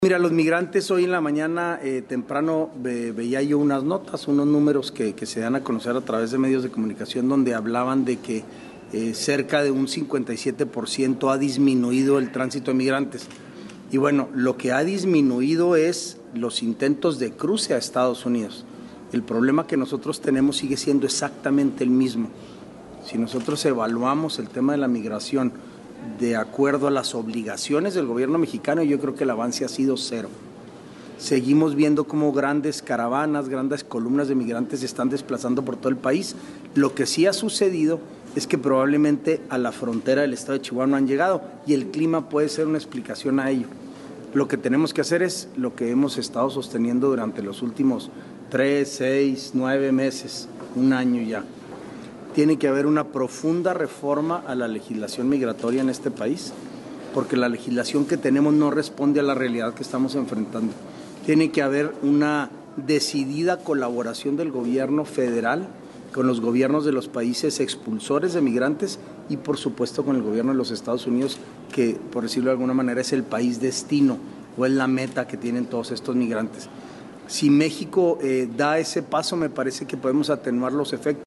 AUDIO: SANTIAGO DE LA PEÑA, TITULAR DE LA SECRETARÍA GENERAL DE GOBIERNO